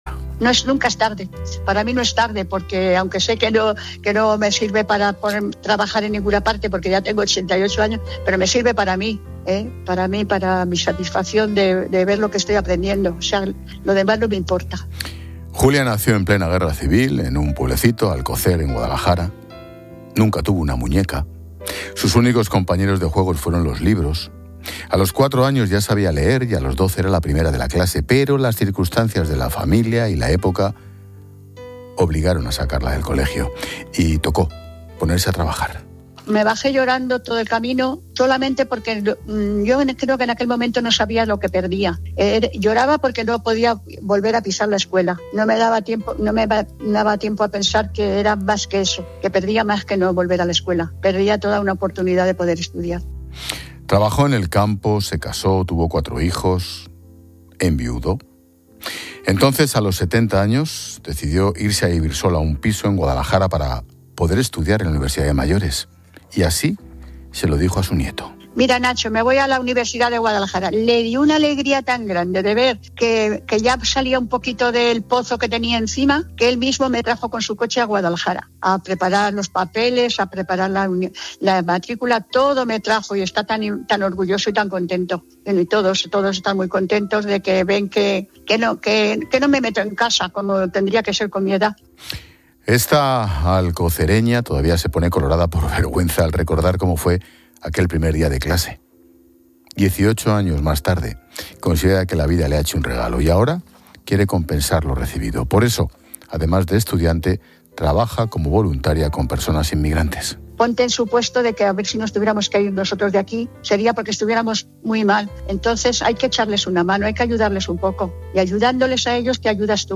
"Sé que no me sirve para poder trabajar en ninguna parte, pero para mí es una satisfacción estudiar astrobiología en la UNED", ha explicado en el programa 'La Linterna' de COPE con Ángel Expósito.